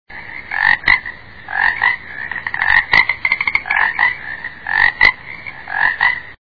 دانلود صدای چند قورباغه از ساعد نیوز با لینک مستقیم و کیفیت بالا
جلوه های صوتی
برچسب: دانلود آهنگ های افکت صوتی انسان و موجودات زنده دانلود آلبوم صدای قورباغه (قور قور) از افکت صوتی انسان و موجودات زنده